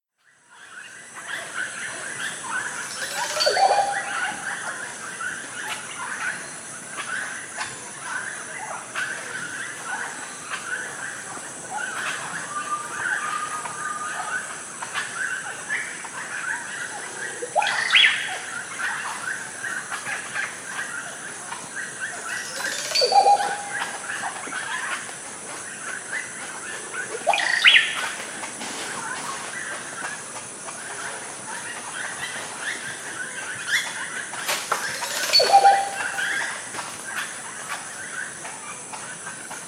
oropendola bird singing.wav
Original creative-commons licensed sounds for DJ's and music producers, recorded with high quality studio microphones.
oropendola_bird_singing_gjp.ogg